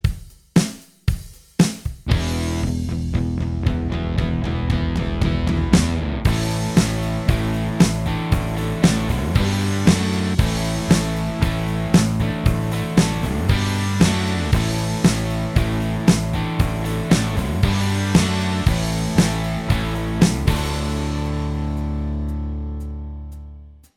Minus Guitars Rock 3:29 Buy £1.50